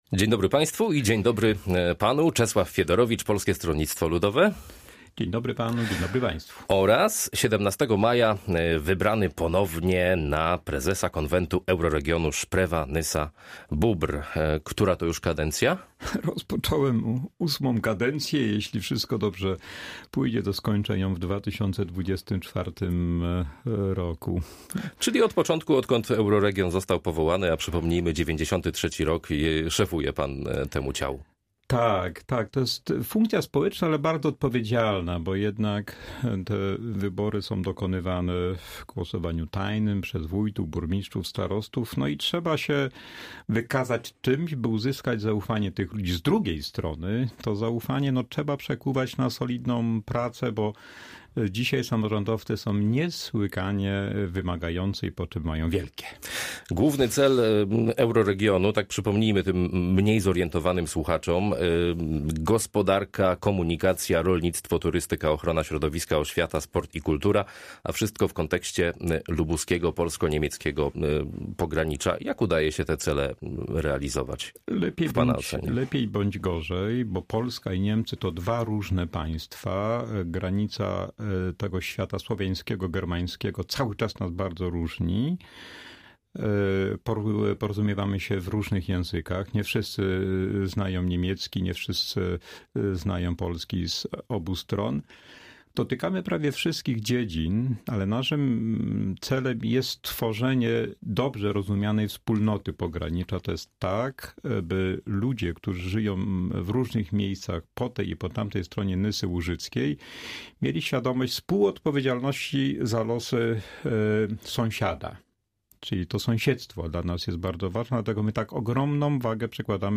Z członkiem PSL i prezesem konwentu Euroregionu Sprewa-Nysa-Bóbr rozmawia